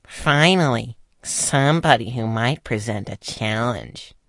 声道立体声